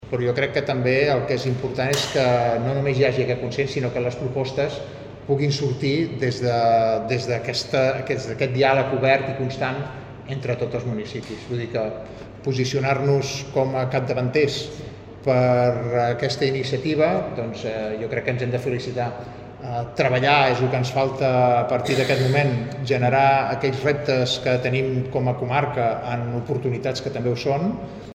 L’alcalde de Sant Feliu de Guíxols, Carles Motas, celebra l’organització de la primera fira comarcal al municipi i considera que, a partir d’aquesta primera edició, cal el treball conjunt amb la resta de municipis del territori.